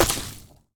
etfx_explosion_lava.wav